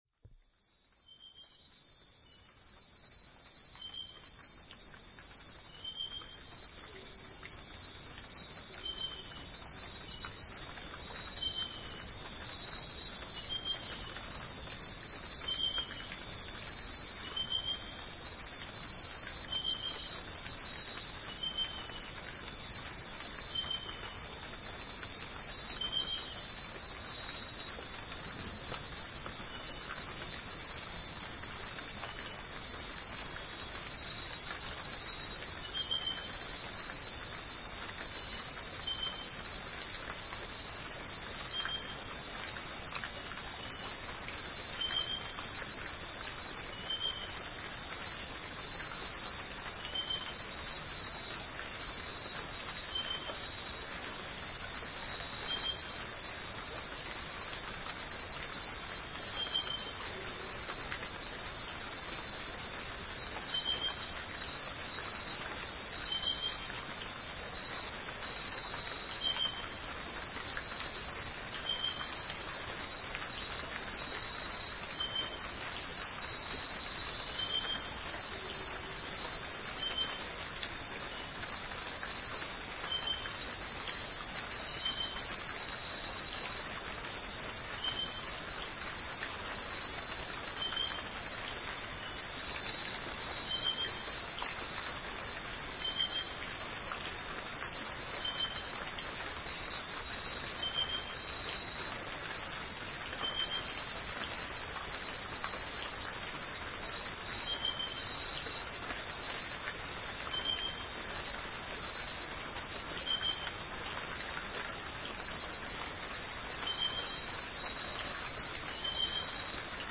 Listen to this one with your eyes closed, and imagine for a moment that you are falling asleep to the sound of rain on the roof, far away in the Amazon forest.
amazonrainynight.mp3